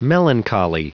Prononciation du mot melancholy en anglais (fichier audio)
Prononciation du mot : melancholy